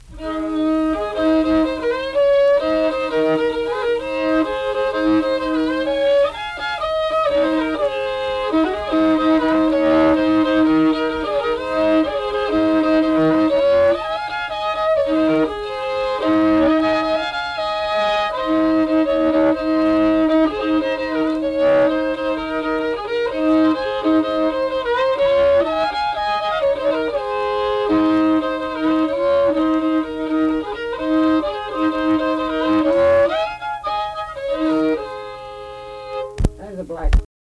Unaccompanied fiddle music and accompanied (guitar) vocal music
Instrumental Music
Fiddle